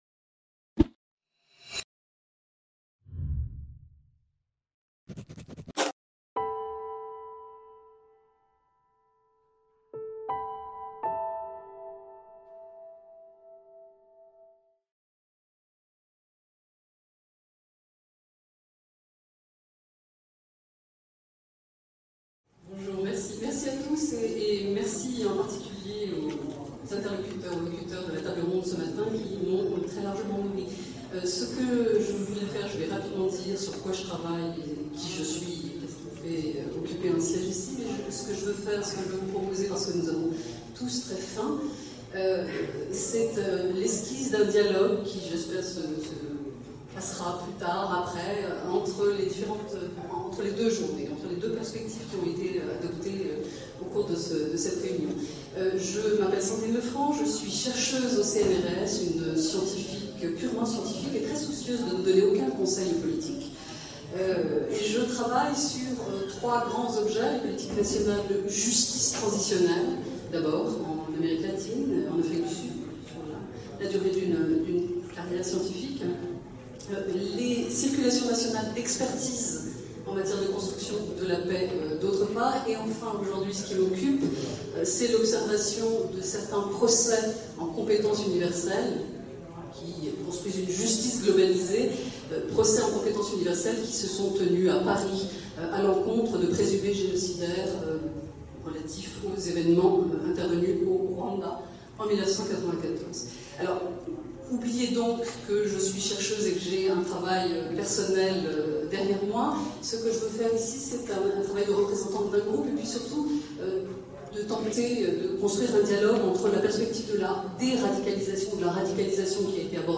Colloque de Tunis du 3-4 Juillet 2017 Le Panel International sur la Sortie de la Violence s’est réuni à Tunis le 3 et 4 Juillet 2017.